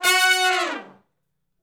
060 Hi Short Falloff (F#) uni.wav